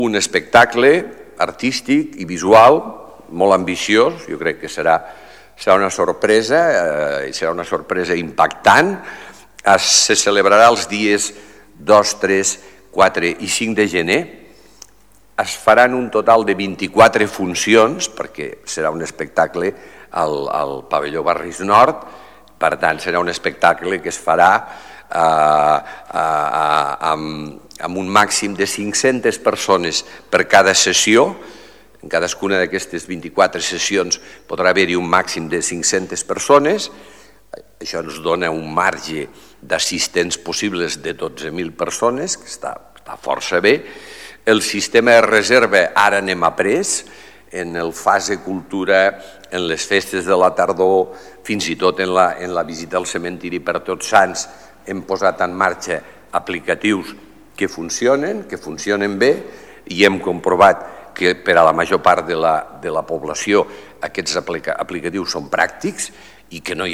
tall-de-veu-del-paer-en-cap-miquel-pueyo-sobre-la-campanya-de-nadal